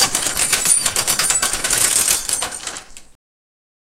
Line: "RELOADING, COVER ME!" 0:04 RAILGUN RELOAD SOUND RELOADING WEAPON 0:04
railgun-reload-sound-relo-x4xpyps3.wav